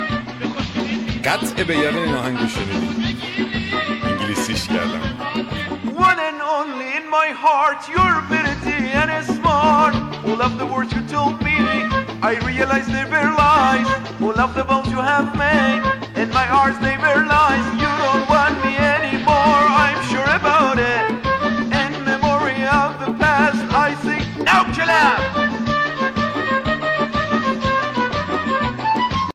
ورژن انگلیسی خارجی